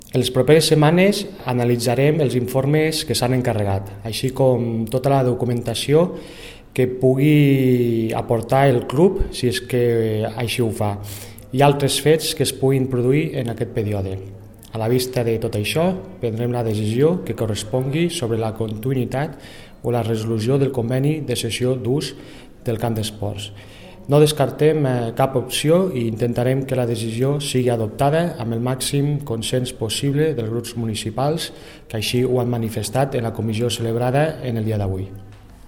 tall-de-veu-del-regidor-desports-ignasi-amor